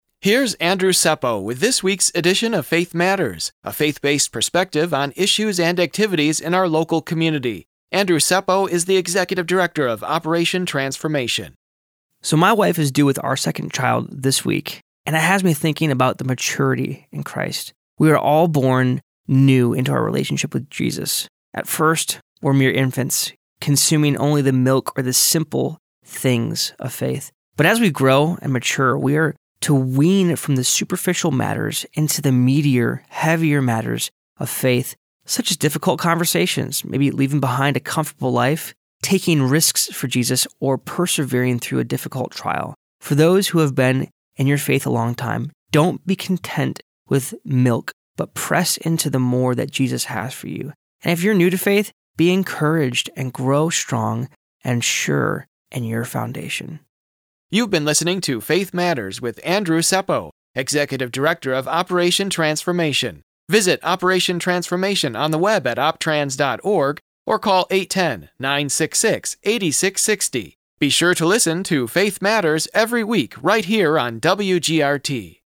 Faith Matters is a weekly radio feature that airs every Monday on WGRT 102.3 FM. Featuring information and commentary about spiritual issues - nationally and in your local community.